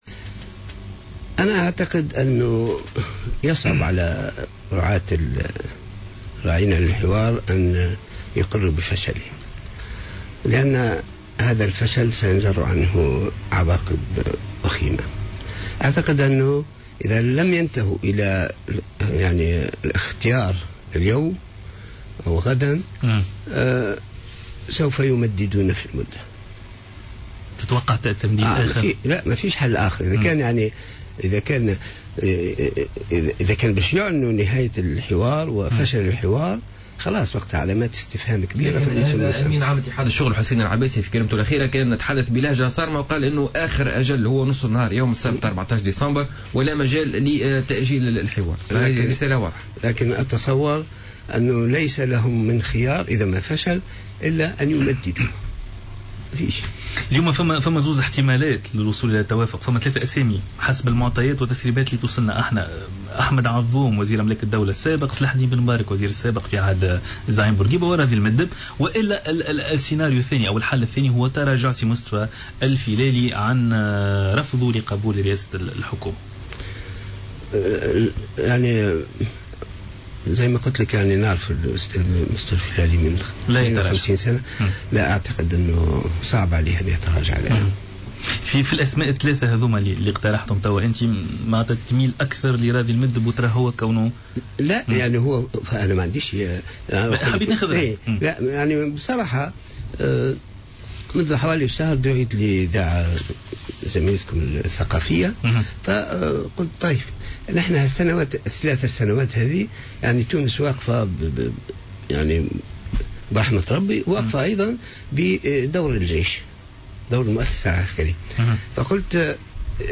مداخلة له اليوم على "جوهرة أف أم"